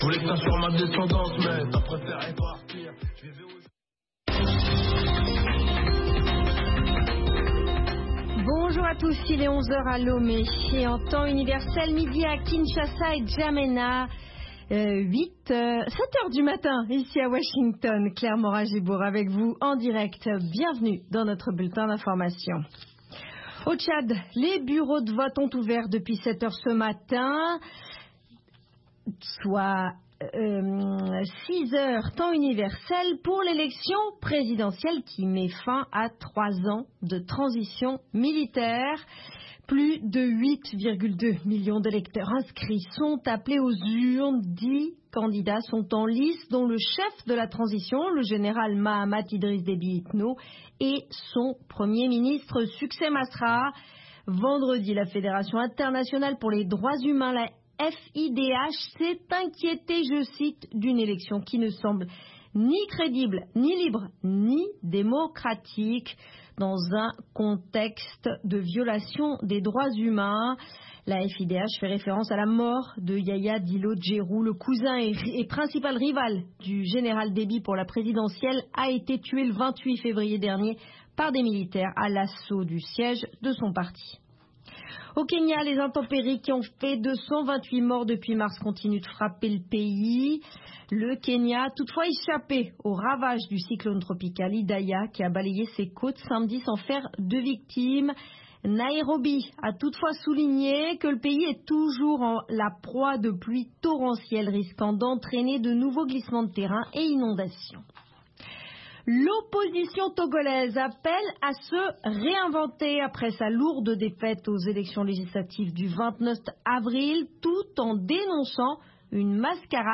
Bulletin d’information de 15 heures
Bienvenu dans ce bulletin d’information de VOA Afrique.